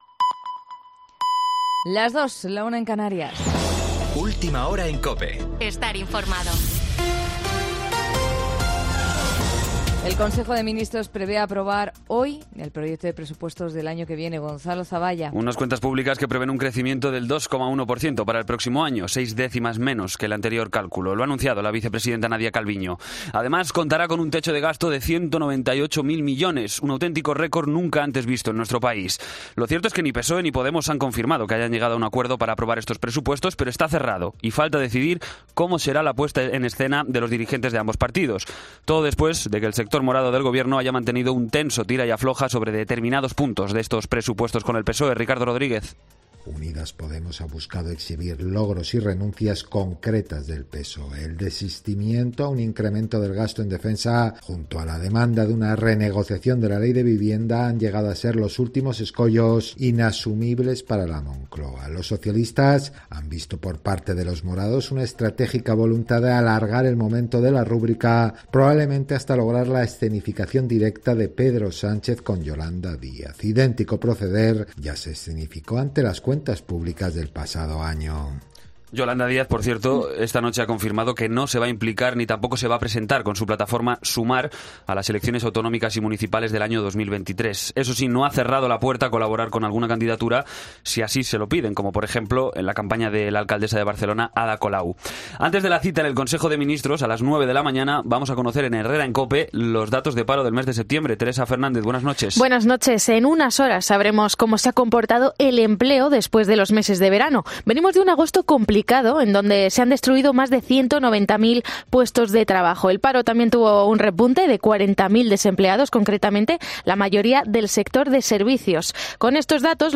Boletín de noticias COPE del 04 de octubre a las 02:00 hora